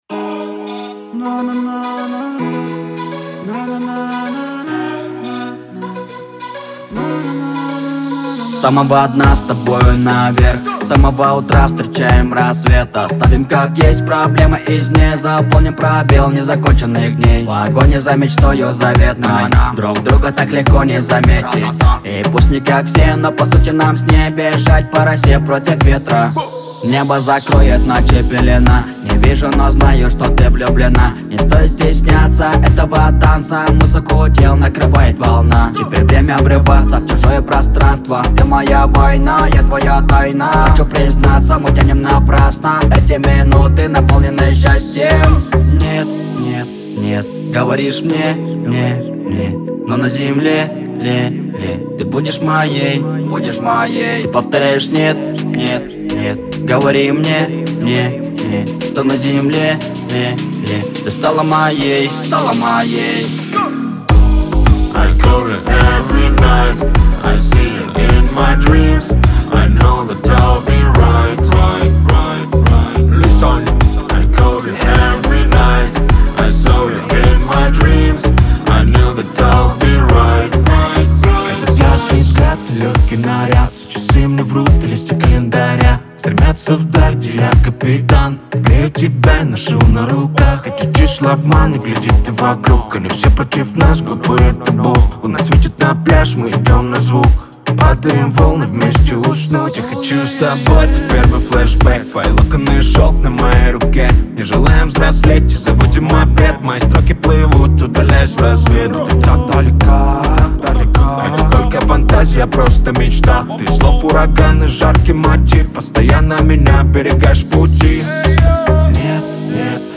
это энергичная композиция в жанре хип-хоп с элементами R&B